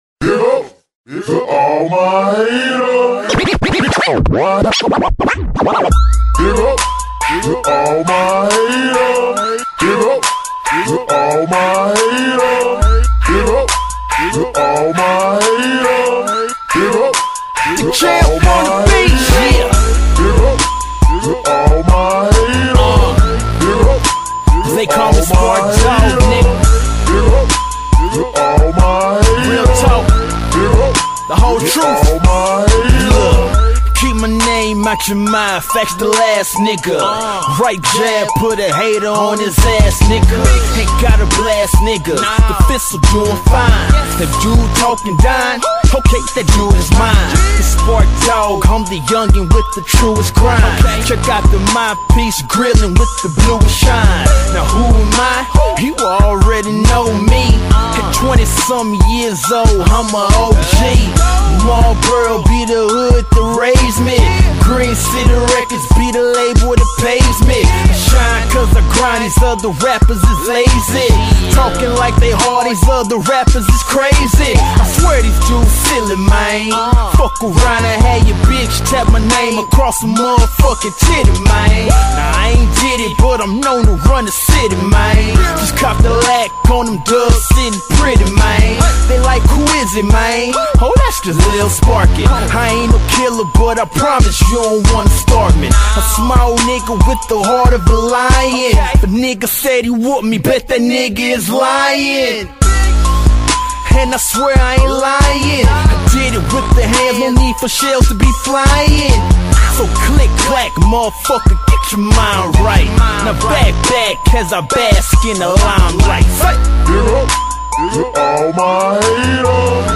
RAP.